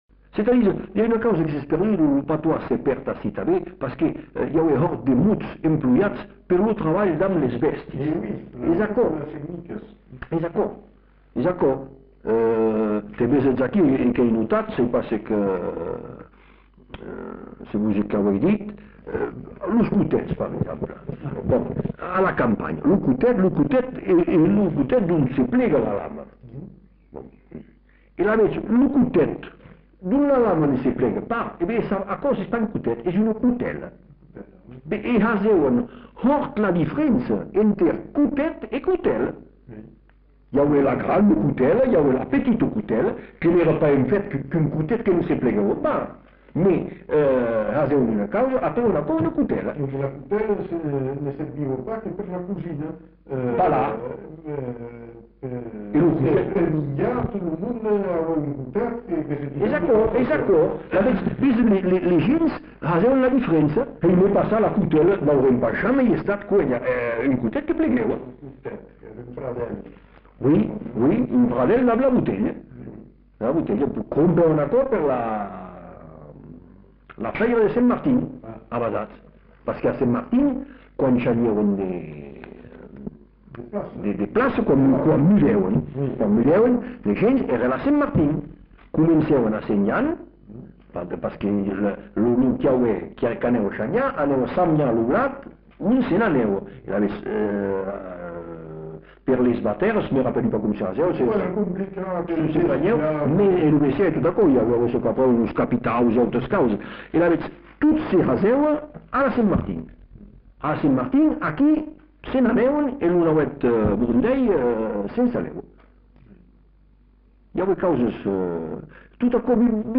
Aire culturelle : Bazadais
Lieu : Bazas
Genre : témoignage thématique